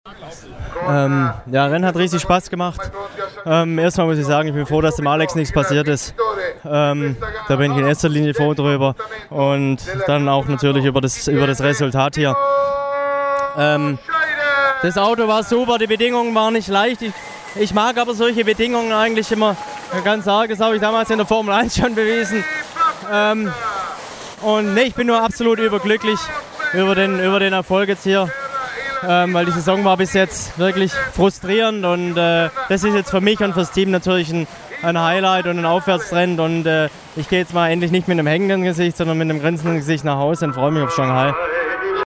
Markus Winkelhock O Ton nach dem Rennen in Adria (MP3)
Markus-Winkelhock_O_Ton_nach_Rennen_Adria_2010.mp3